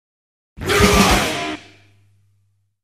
BTC-alert.mp3